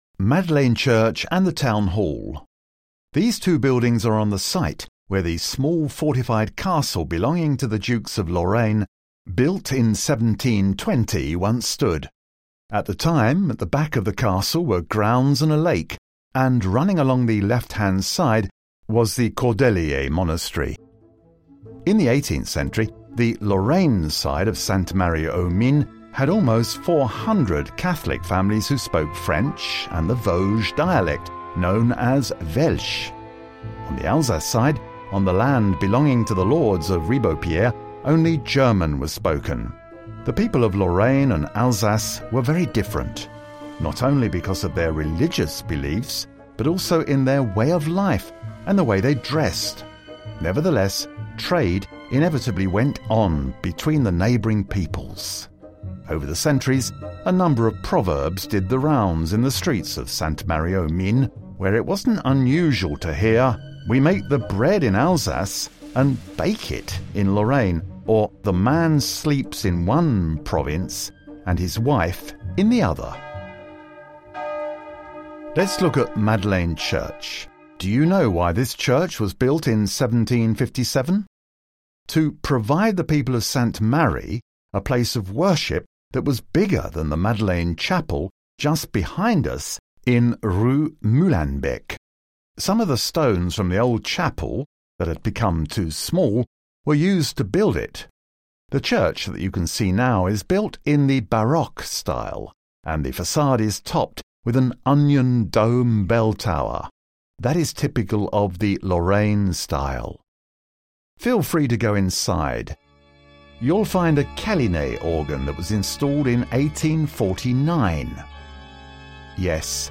• Audioguide 6 : l’église de la Madeleine et l’Hôtel de ville de Sainte-Marie-aux-Mines : fr